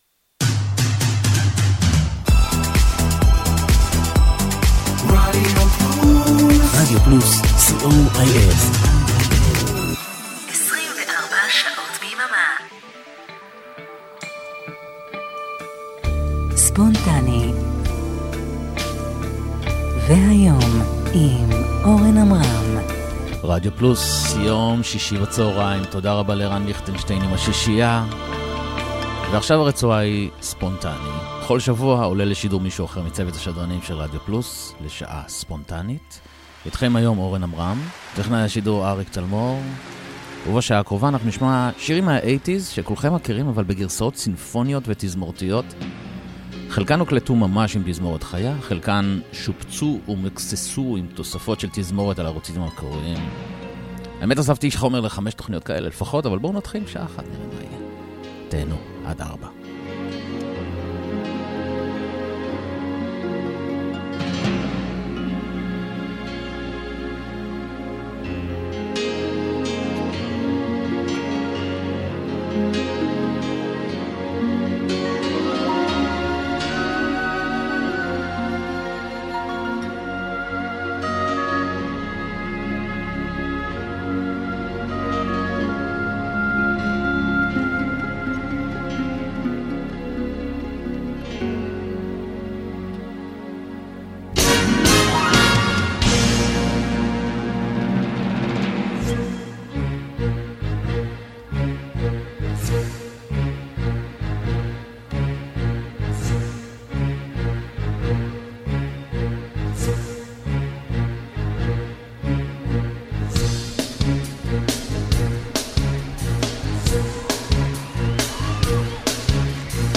a collection of 80’s symphonic versions.